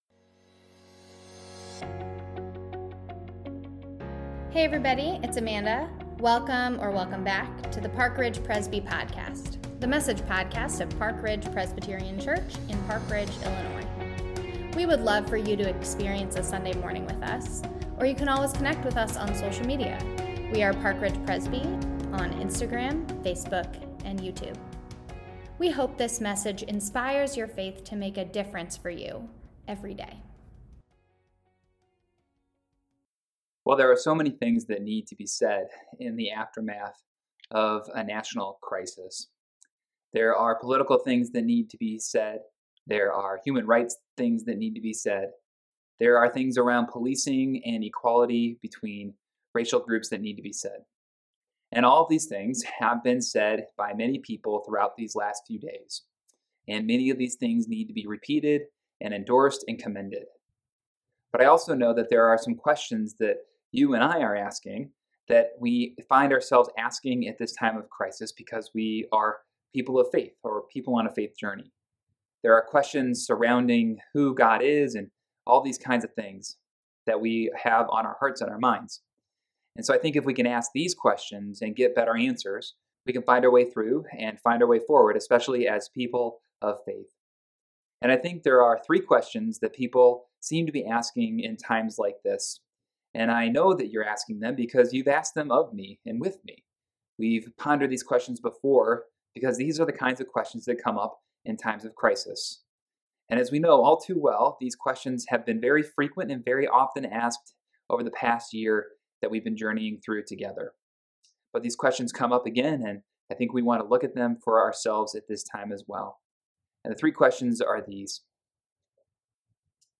2021-01-10-Sermon.mp3